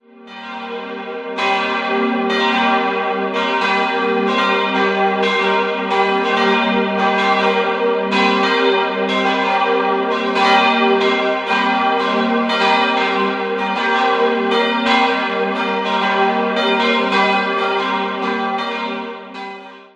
Die Gläubigen versammeln sich auf drei Seiten um den Altar, dem optischen Zentrum des nüchternen Raumes. 3-stimmiges TeDeum-Geläute: g'-b'-c'' Die Glocken wurden 1969 von der Gießerei Friedrich Wilhelm Schilling in Heidelberg gegossen und wiegen 957 (Christusglocke), 571 (Willibaldsglocke) und 392 kg (Johannes-d.T.Glocke).